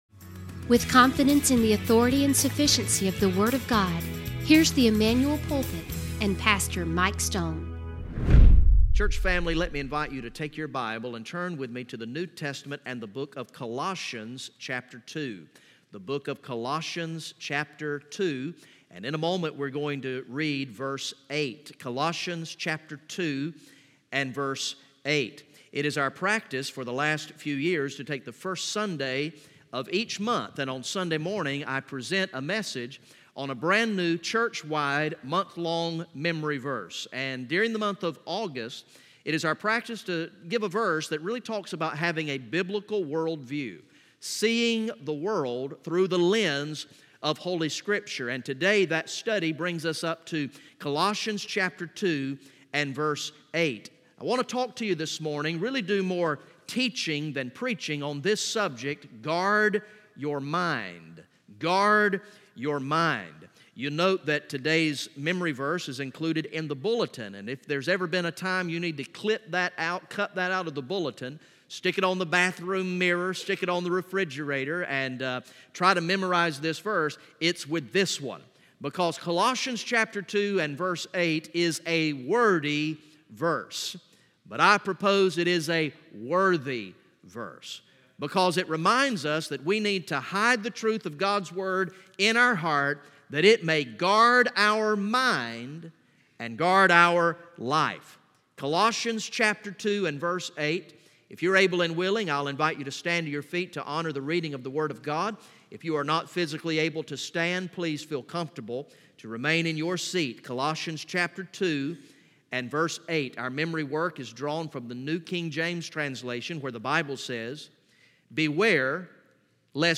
From the morning worship service on Sunday, August 4, 2019